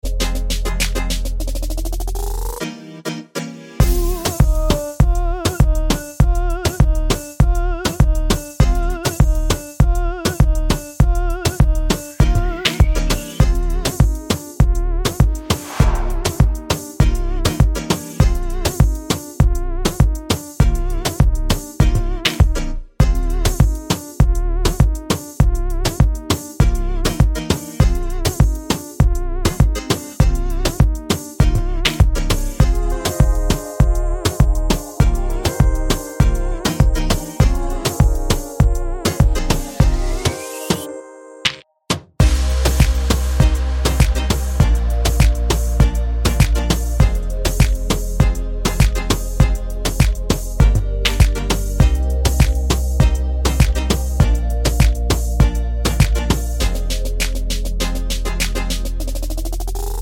no Backing Vocals Finnish 3:03 Buy £1.50